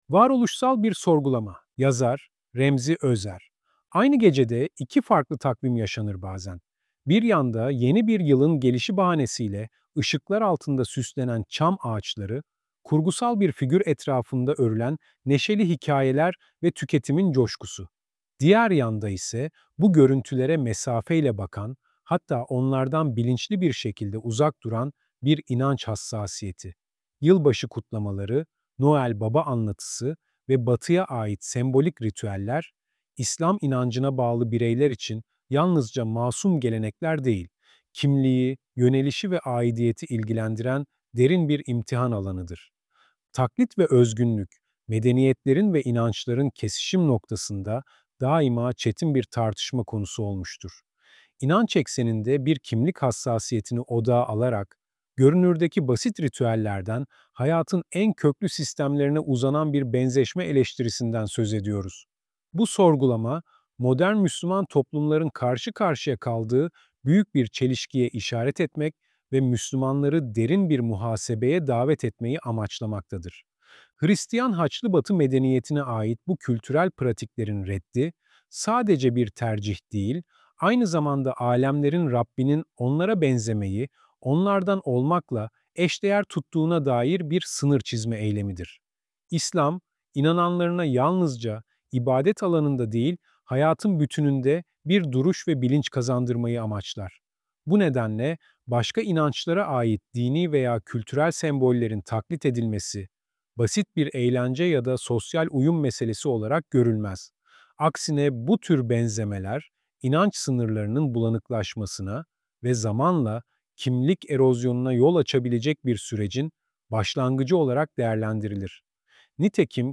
Geri Al Oynat İleri Al Oynat: Varoluşsal Bir Sorgulama 0:00 Sesi Kapat Kapak Kapalı İndir Ayarlar Bu ses yapay zeka tarafından oluşturulmuştur Aynı gecede iki farklı takvim yaşanır bazen .